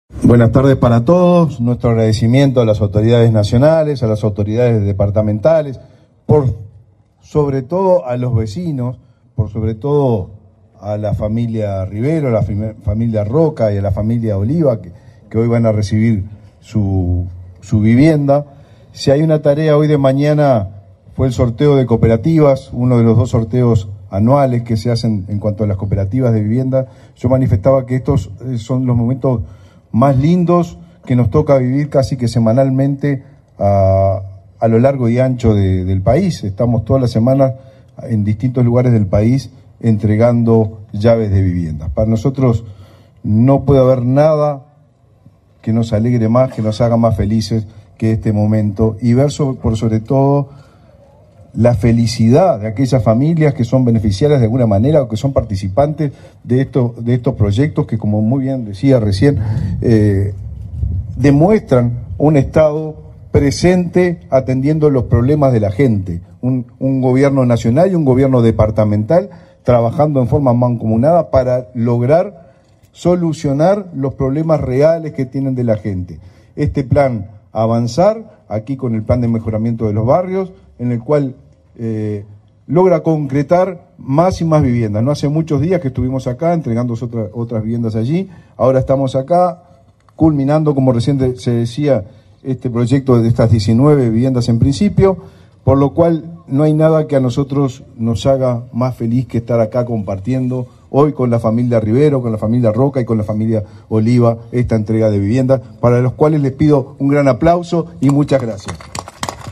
Palabras del ministro de Vivienda y Ordenamiento Territorial, Raúl Lozano
En la oportunidad, realizó declaraciones.